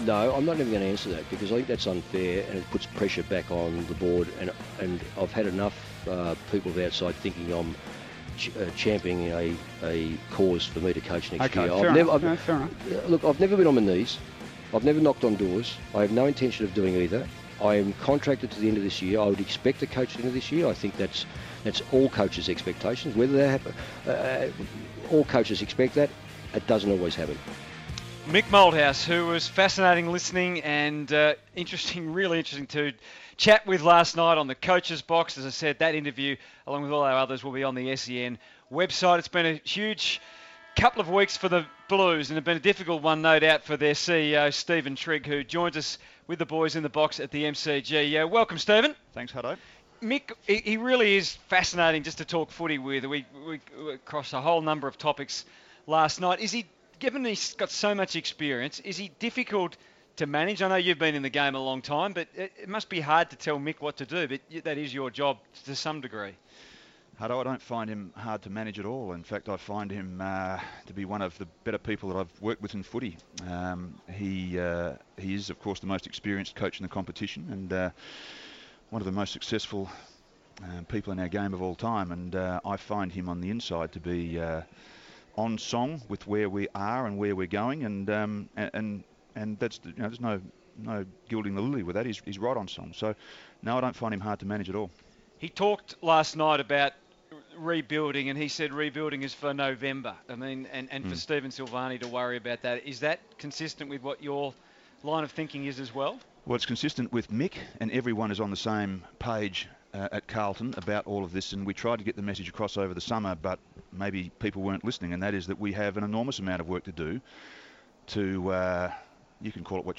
joined the boys for a heated chat about the Blues.